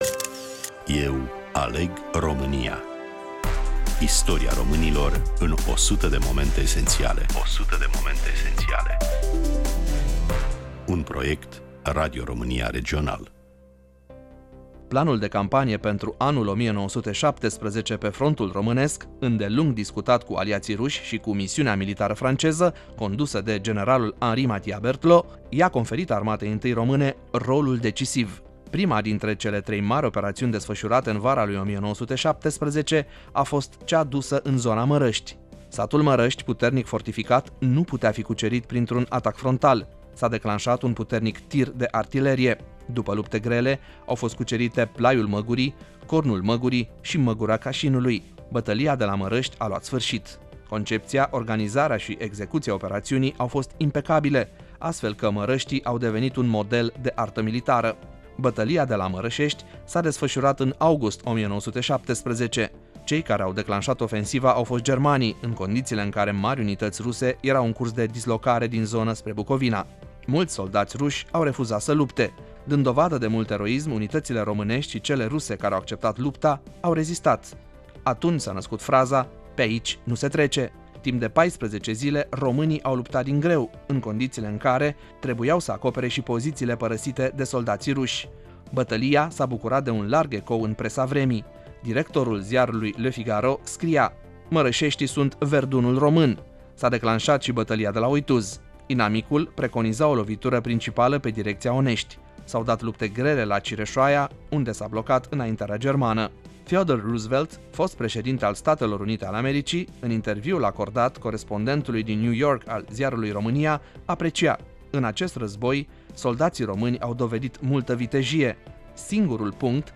Prezentator / voice over